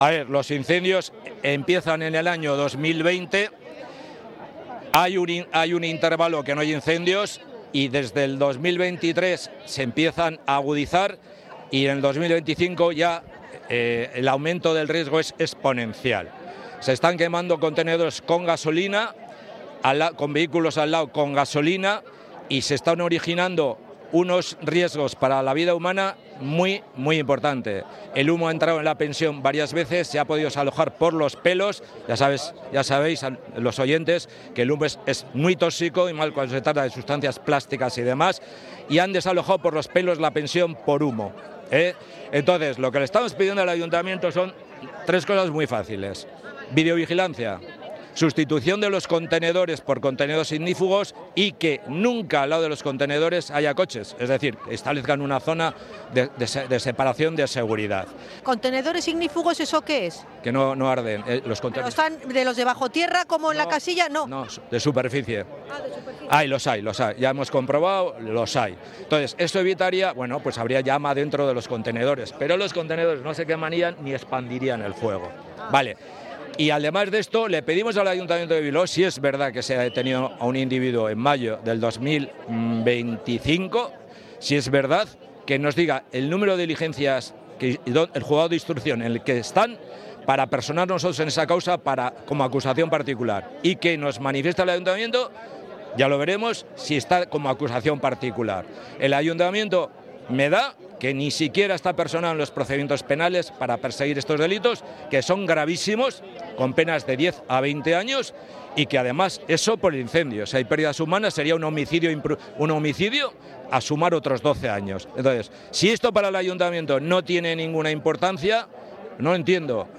Hablamos con los vecinos que se han concentrado para denunciar la falta de seguridad en el barrio